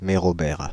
Mérobert (French pronunciation: [meʁɔbɛʁ]
Fr-Paris--Mérobert.ogg.mp3